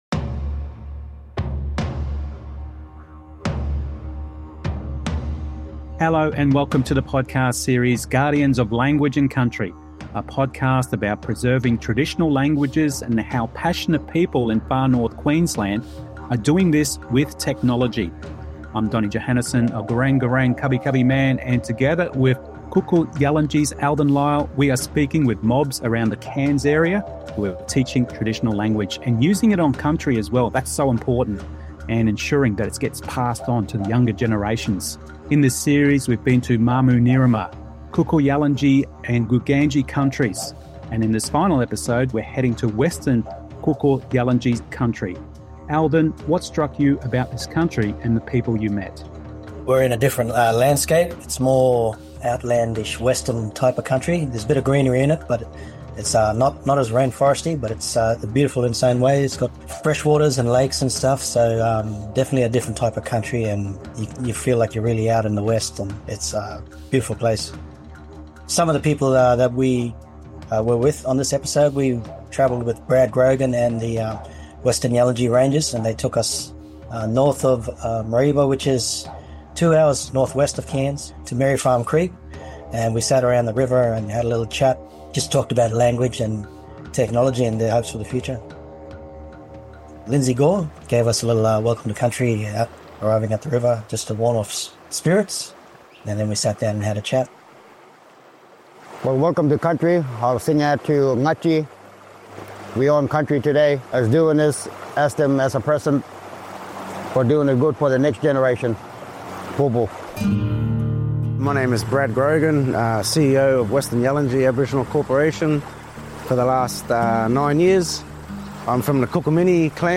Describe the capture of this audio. From snakes interrupting interviews to heartfelt reflections on legacy and learning, this episode is a powerful reminder that language is more than words—it’s identity, connection, and a way home.